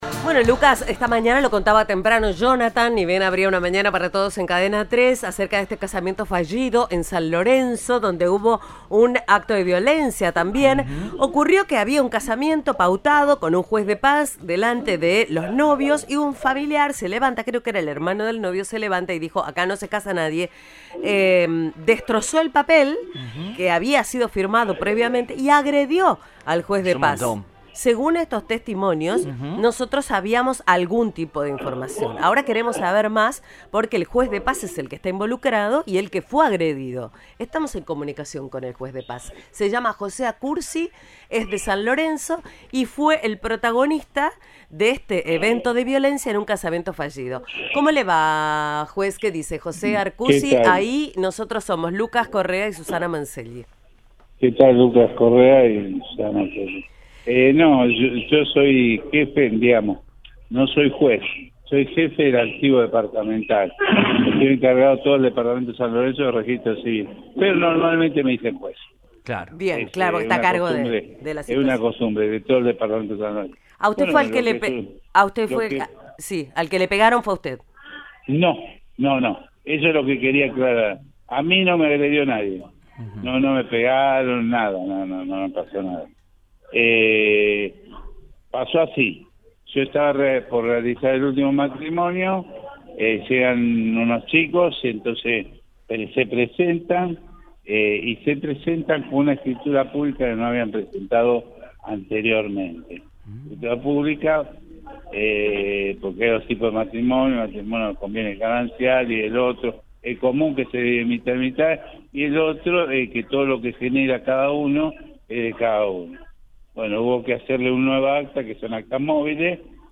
Testimonios de protagonistas en Cadena 3.